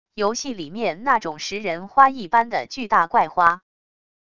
游戏里面那种食人花一般的巨大怪花wav音频